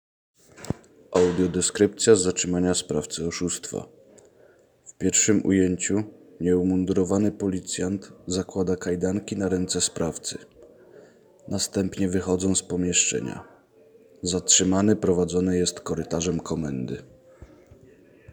Nagranie audio Audiodeskrypcja_oszustwo.m4a